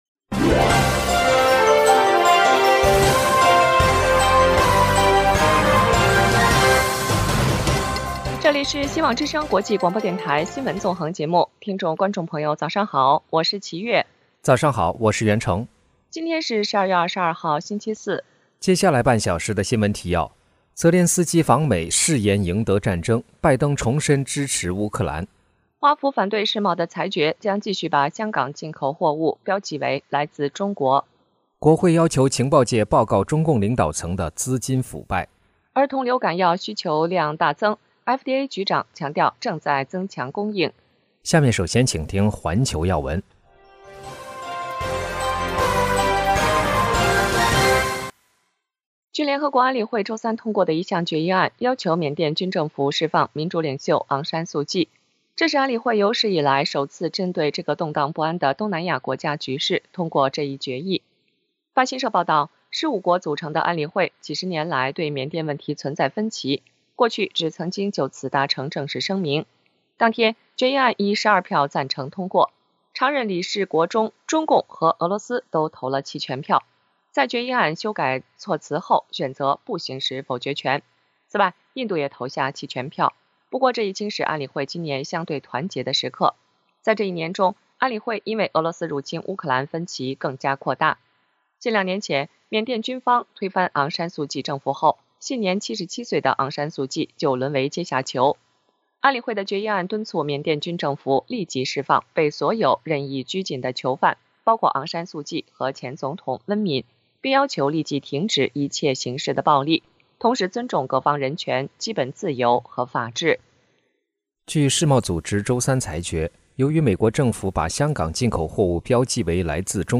FTX创办人同意引渡返美 并在美国接受审判【晨间新闻】